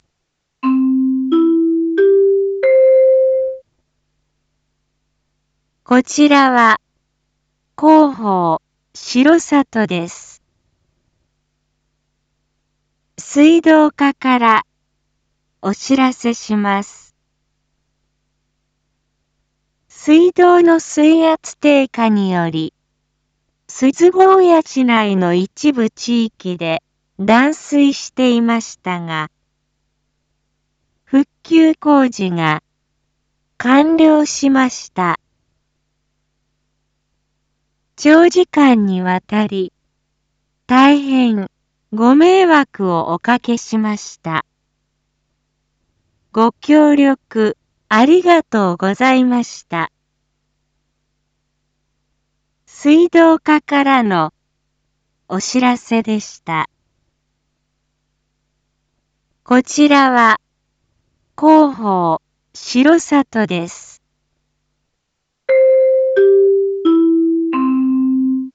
Back Home 一般放送情報 音声放送 再生 一般放送情報 登録日時：2024-01-05 17:31:09 タイトル：R6.1.5錫高野断水 インフォメーション：こちらは、広報しろさとです。